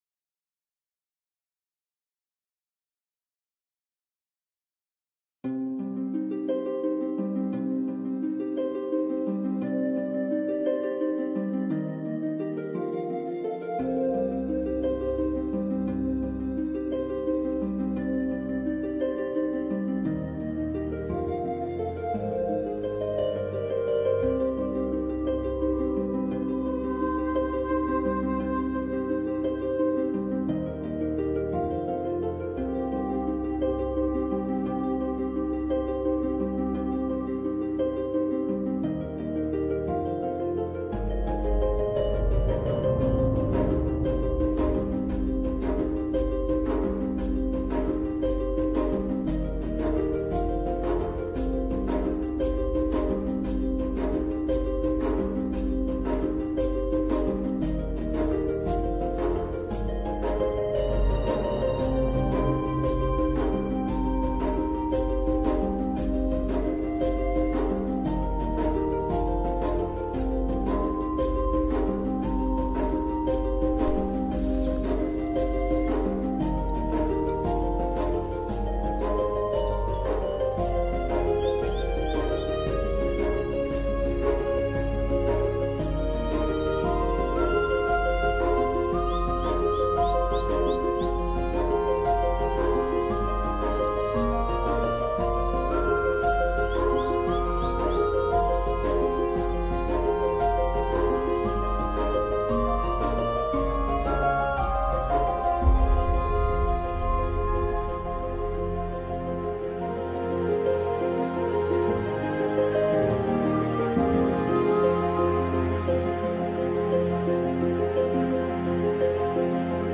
MÚSICA-CELTA-Magia-de-los-bosques-y-gratitud-a-los-árboles.mp3